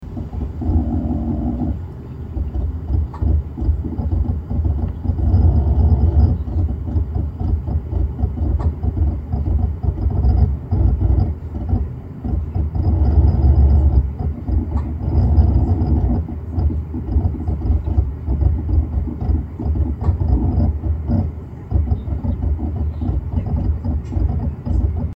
HDD lautes Rattern, nie zuvor passiert, normal?
Nun habe ich viele neue videodateien direkt von meiner nvme auf die Toshiba übertragen, es wurden sogar Geschwindigkeiten von bis zu 350mb/s erreicht, die HDD ist also zum ersten Mal ohne Limitierung durch die langsameren Seagates am Schreiben. Nur klingt sie heute deutlich lauter als zuvor, ein rhytmisches schlagen und lange krrrrrrt geräusche sind auch dabei die ich so noch nie zuvor gehört habe.